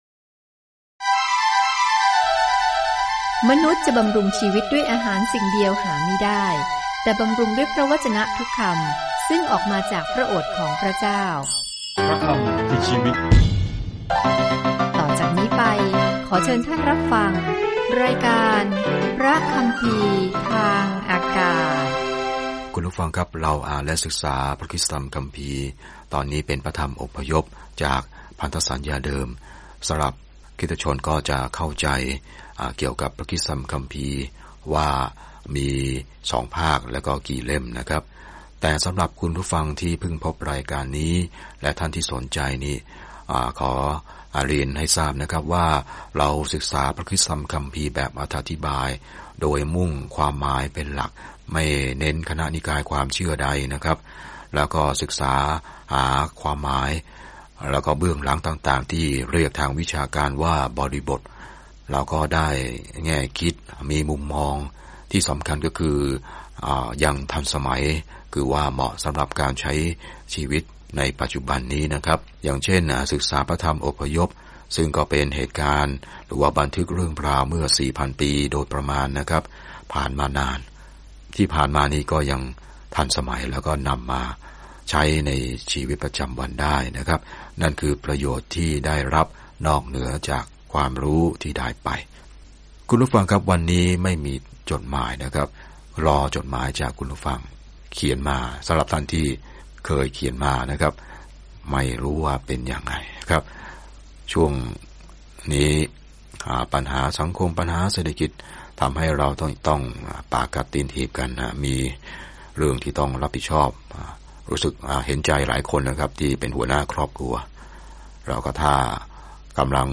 อพยพติดตามการหลบหนีของอิสราเอลจากการเป็นทาสในอียิปต์และบรรยายถึงทุกสิ่งที่เกิดขึ้นระหว่างทาง เดินทางทุกวันผ่าน Exodus ในขณะที่คุณฟังการศึกษาด้วยเสียงและอ่านข้อที่เลือกจากพระวจนะของพระเจ้า